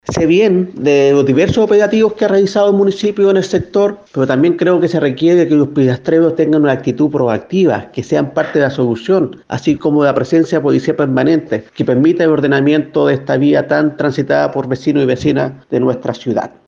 El concejal, Vladímir Valenzuela, hizo un llamado a los locatarios a ponerse de acuerdo con las autoridades y policía para hacer frente al problema de manera efectiva.